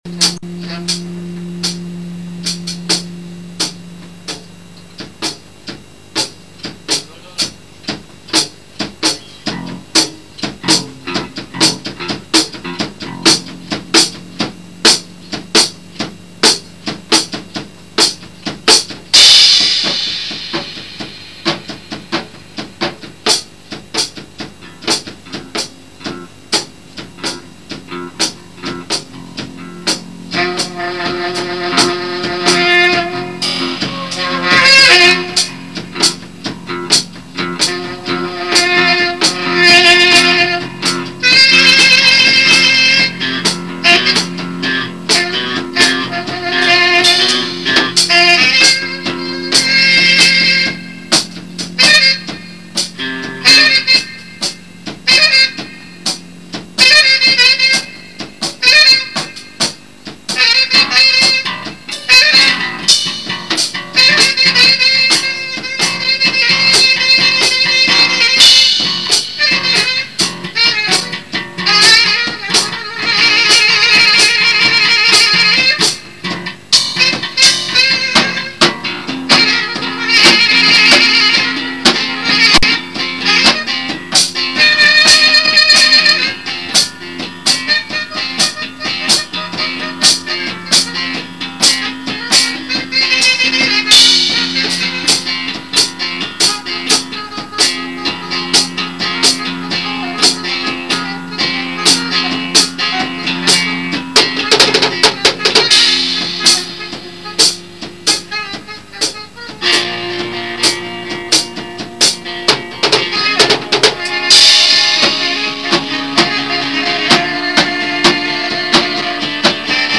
Keyboards
Drums
Flute
Bas guitar
Lead guitar